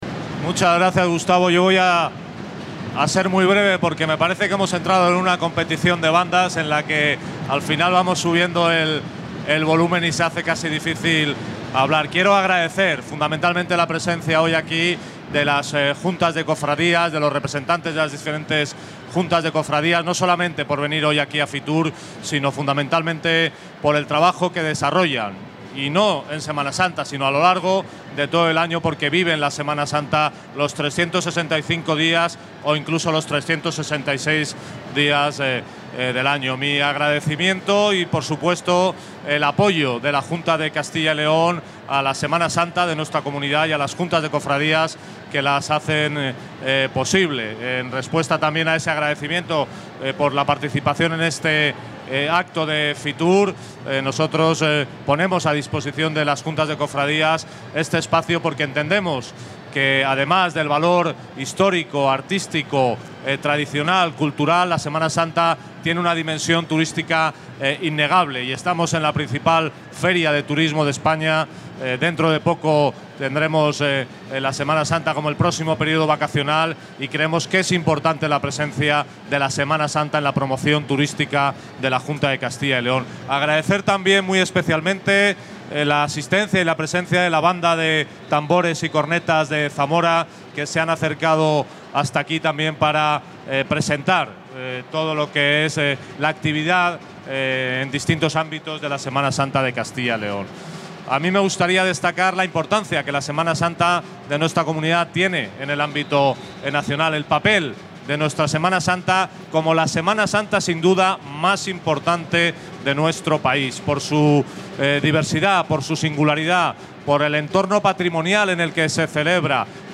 El director general de Turismo, Javier Ramírez, ha presentado hoy en Fitur la Semana Santa en Castilla y León 2018